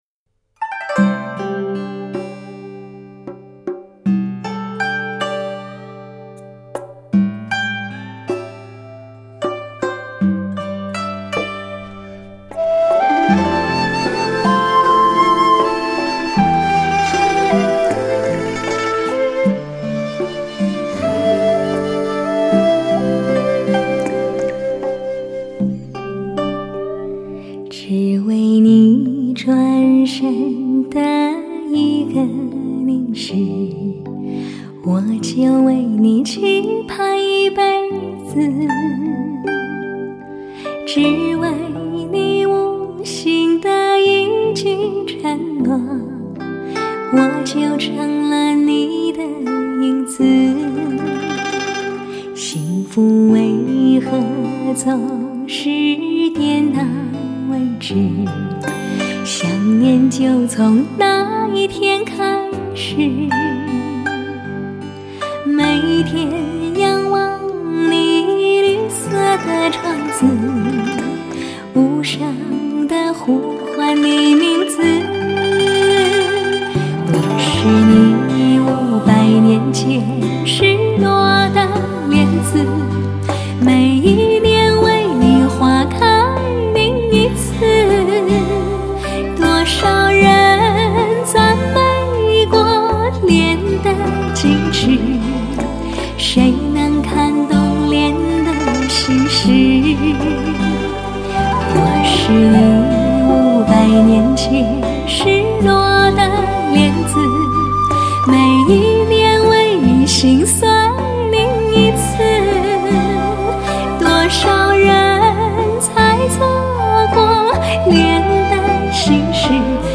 歌手类型: 华语女歌手
音乐类型: 流行音乐/Pop